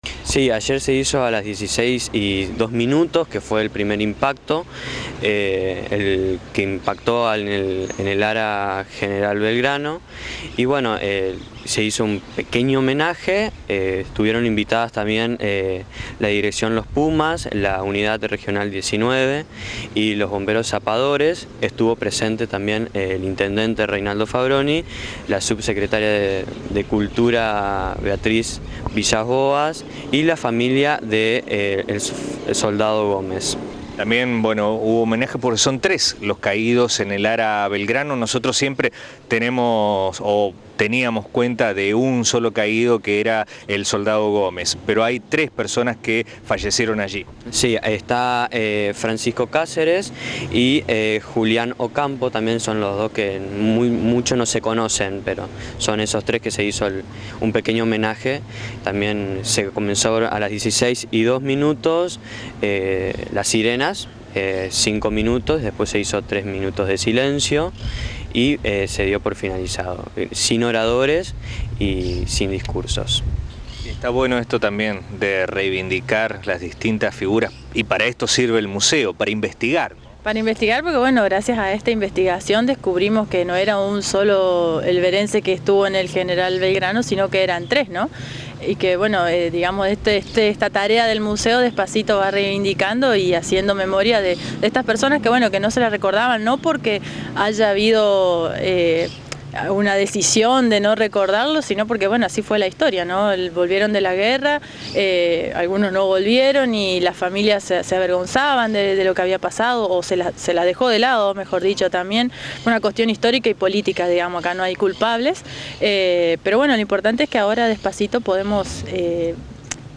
AUDIO: nota con